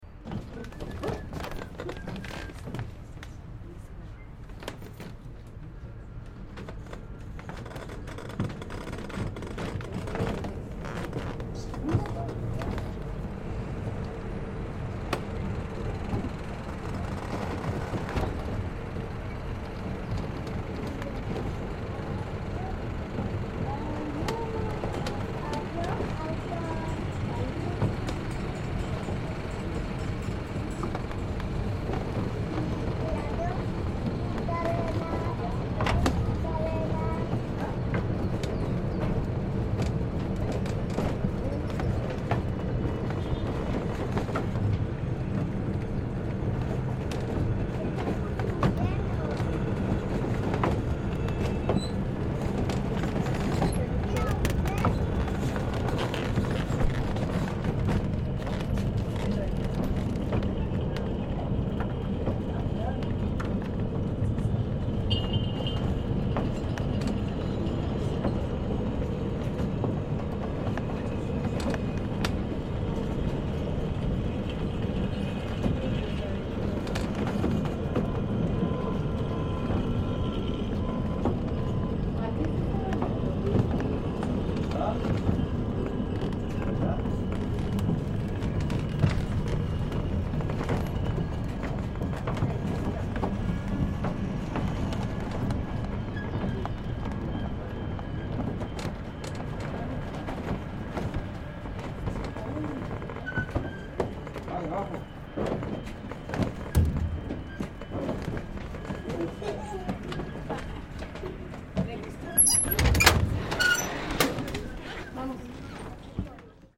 This recording shows off their distinctive clattering sounds, which could only come from this part of the world!
——————— This sound is part of the Sonic Heritage project, exploring the sounds of the world’s most famous sights.